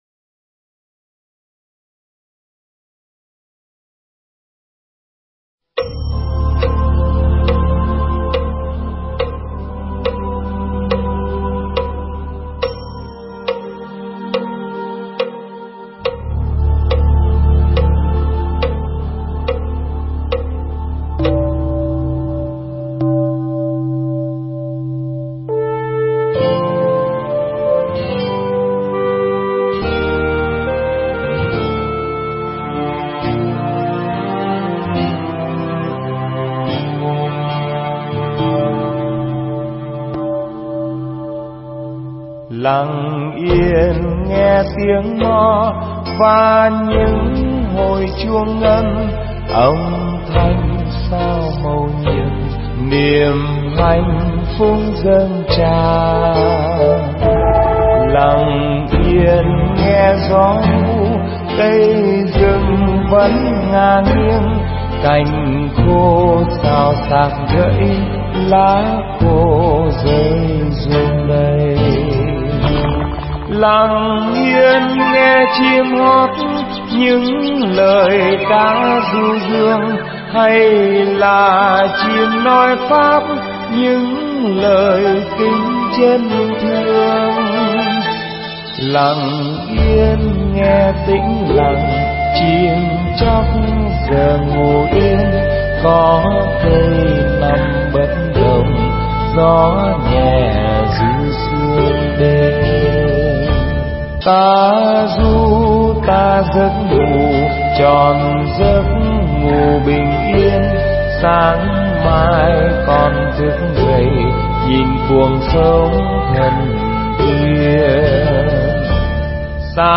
thuyết giảng tại Chùa Huệ Quang, Mỹ